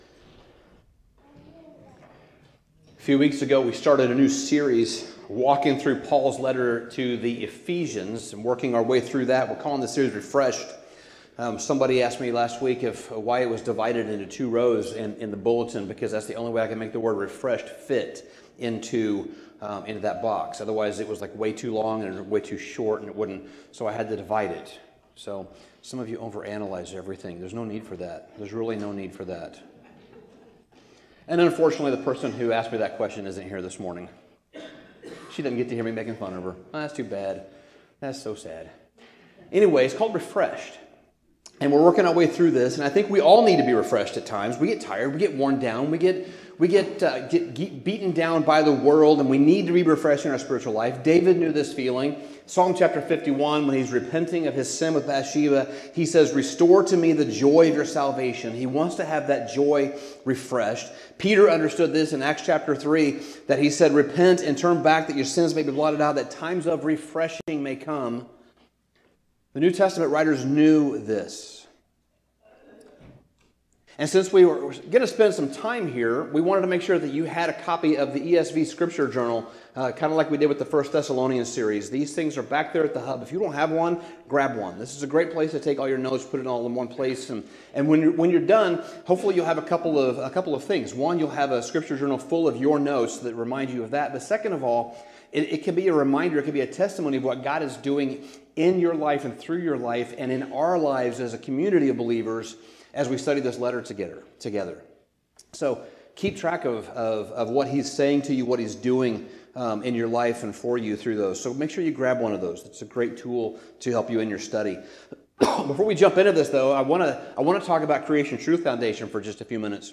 Sermon Summary There was a time when we were far off — cut off from the people of God, strangers to his promises, and living without true hope in the world.